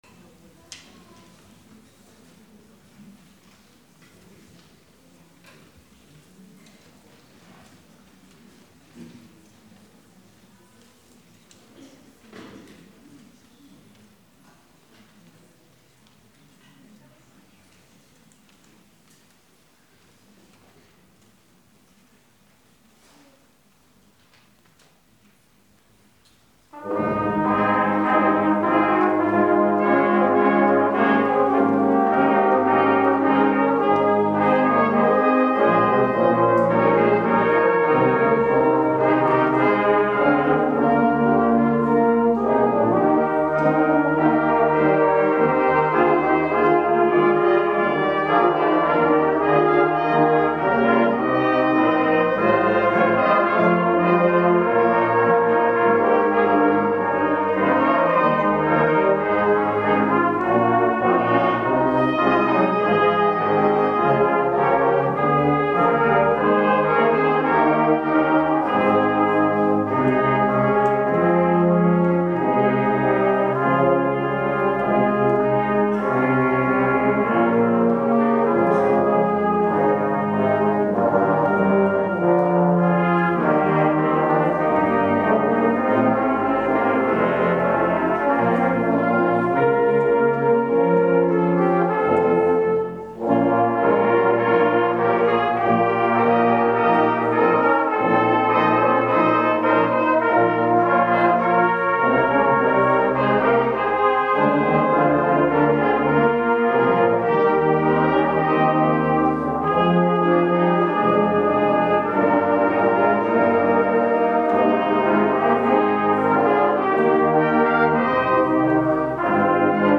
Güglingen - Posaunenchor
Posaunenfeierstunde 2022